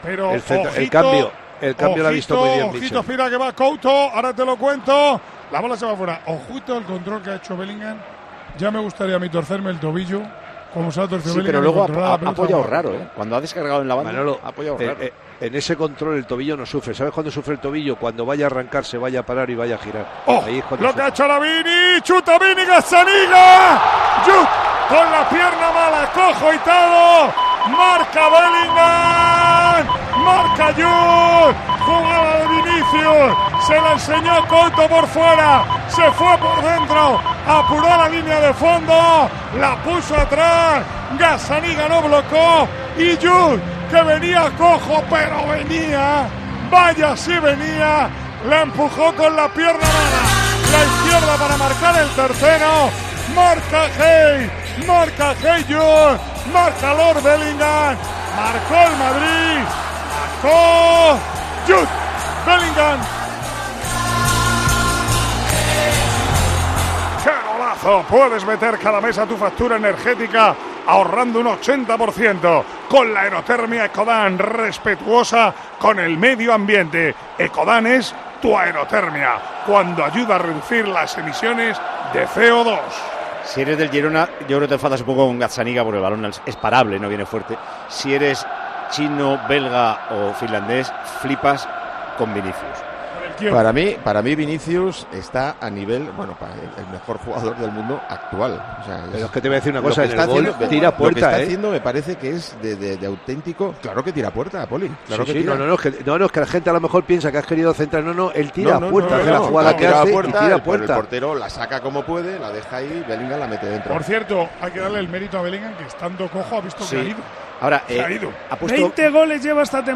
Así vivimos en Tiempo de Juego la retransmisión del Real Madrid - Girona